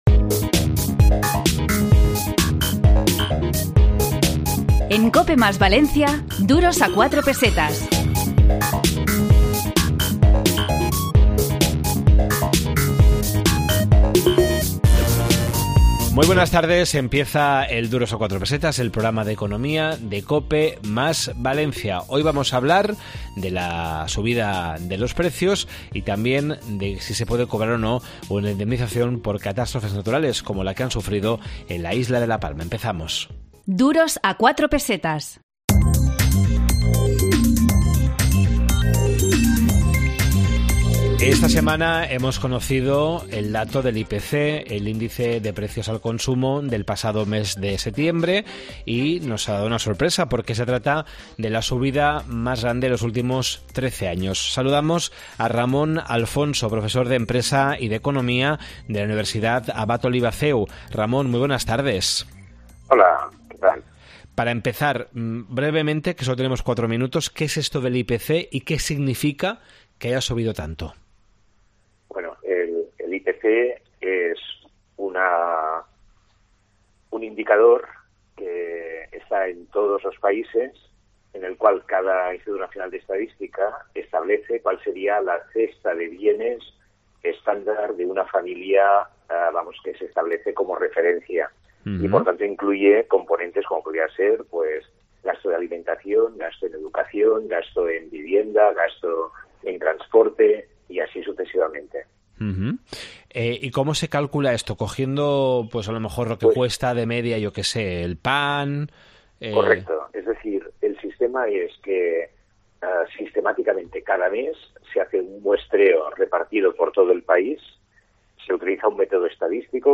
Esta semana en Duros a 4 Pesetas de COPE + Valencia, en el 92.0 de la FM, hemos hablado sobre la morosidad, las reclamaciones al seguro tras las pérdidas por el volcán de La Palma y la subida del índice de precios al consumidor (IPC).